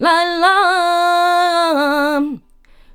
Index of /90_sSampleCDs/USB Soundscan vol.59 - Spanish And Gypsy Traditions [AKAI] 1CD/Partition B/04-70C RUMBA